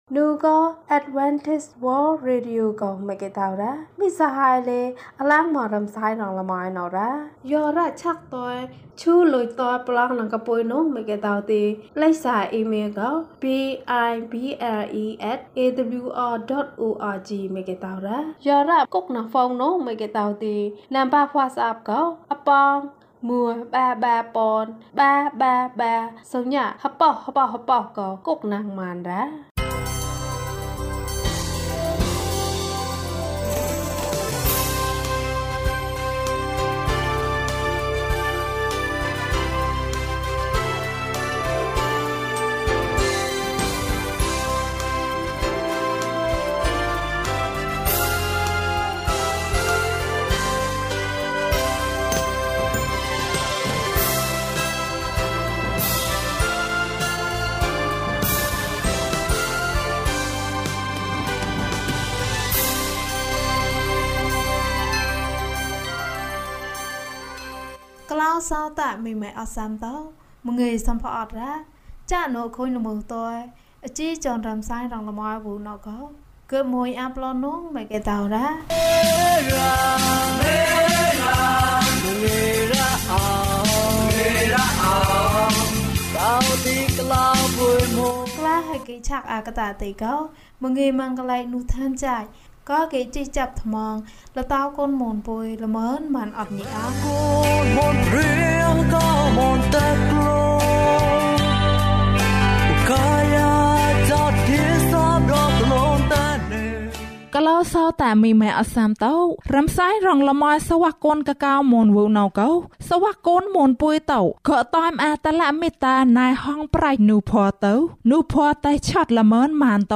ယေရှု၌ ကျွန်ုပ်တို့၏ဆုတောင်းချက်။၀၁ ကျန်းမာခြင်းအကြောင်းအရာ။ ဓမ္မသီချင်း။ တရားဒေသနာ။